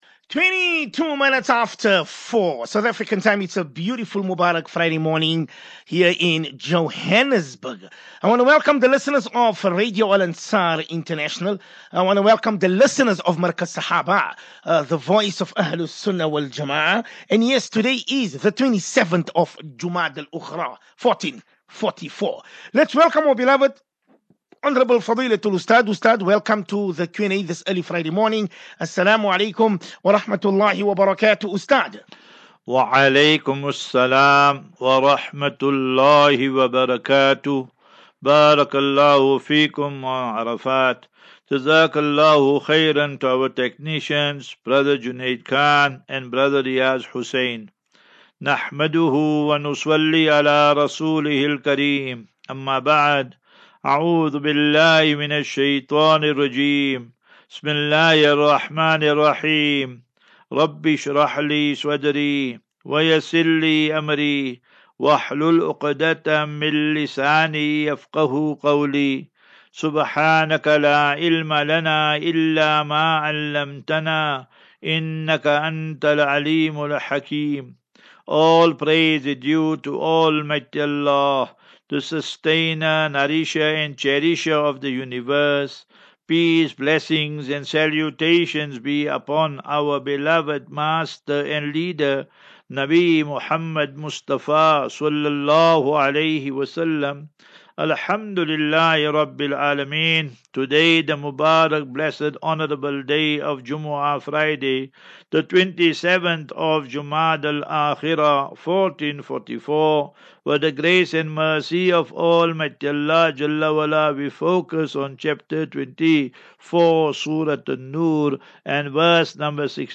QnA